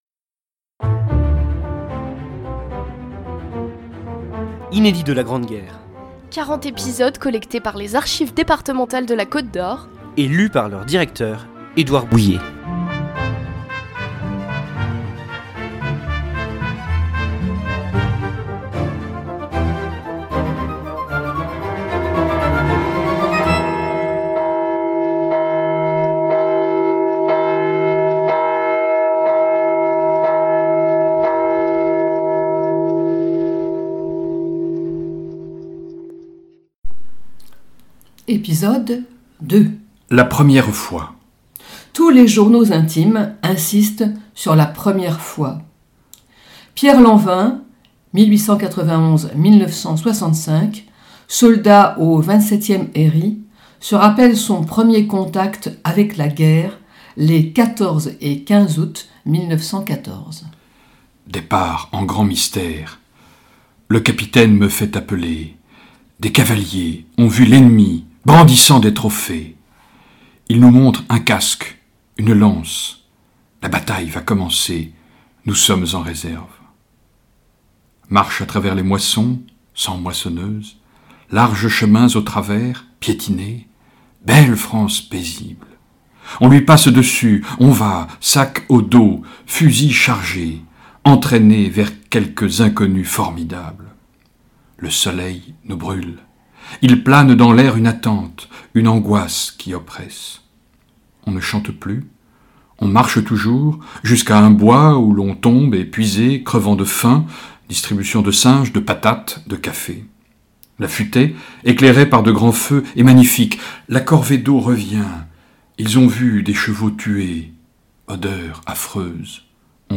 Un feuilleton de lettres et de journaux intimes à suivre jusqu’au 11 novembre 2018, pour célébrer le centenaire de l’armistice de la Grande Guerre, signé à Rethondes le 11 novembre 1918 !